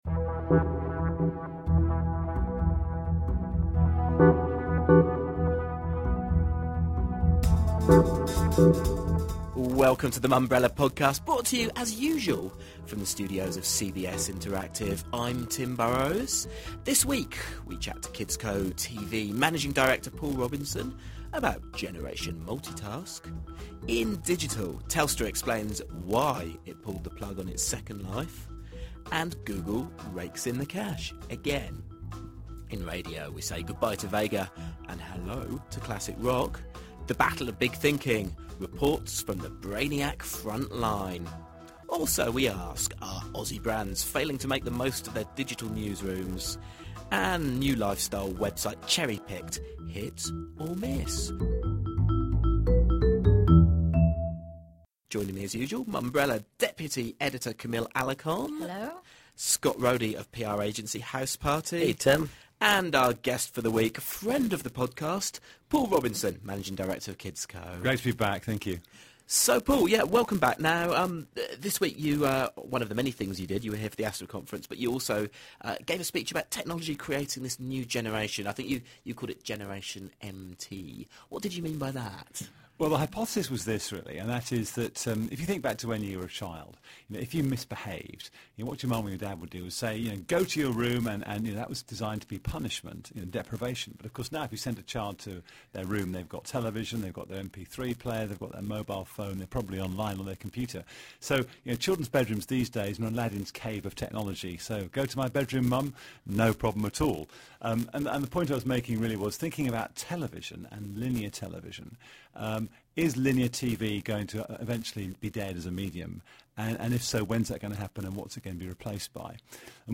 (Apologies for the annoying crackling on some of the audio file – this occurred during the recording process)